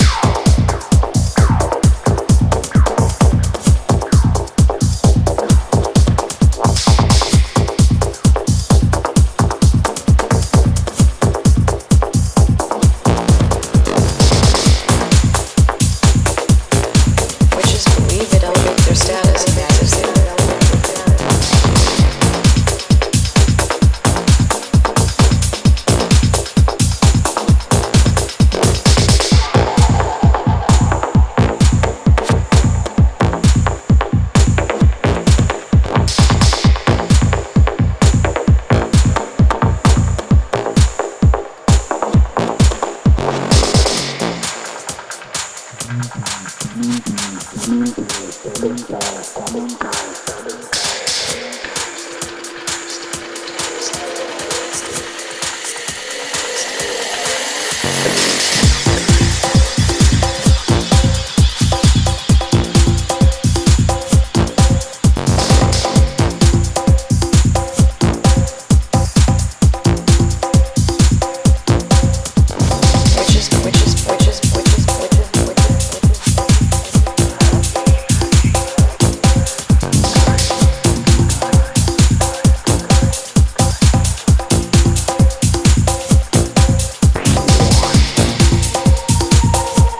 鋭いコンガがダークで圧の強いベースラインと共にグルーヴを加速させる